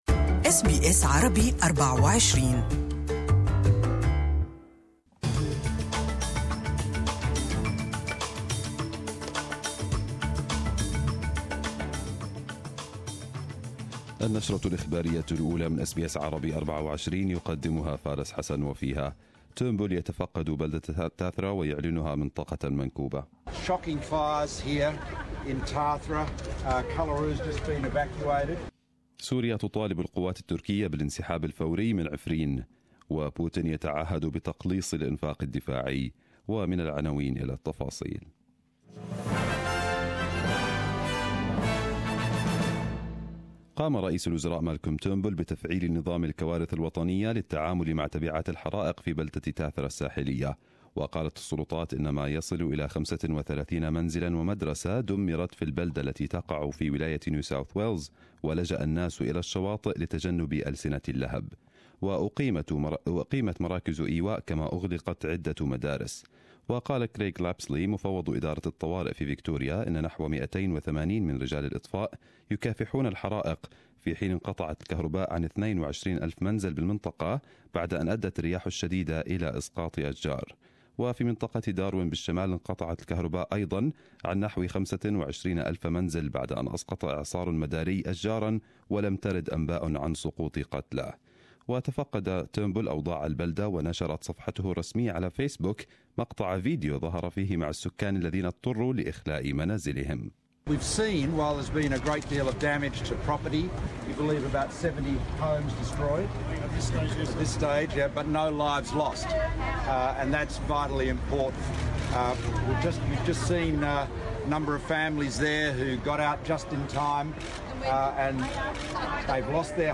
Arabic News Bulletin 20/03/2018